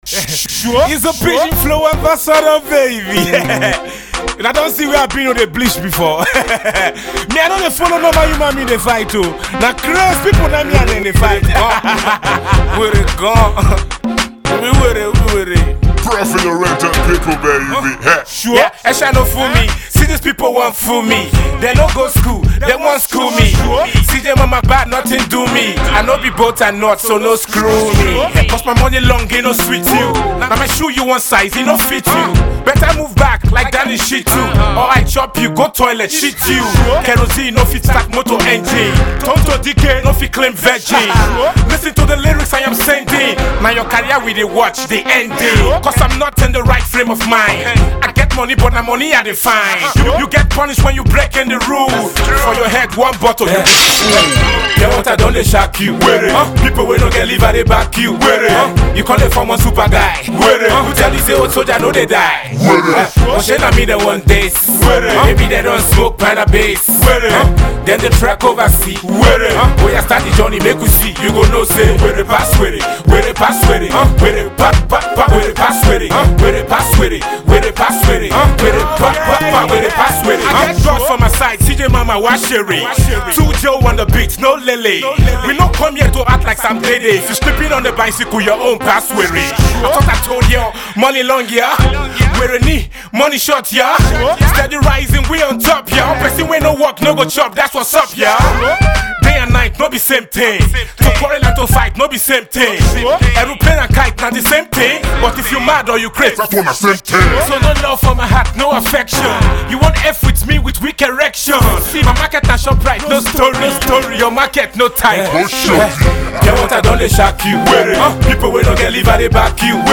This is the definition of street hop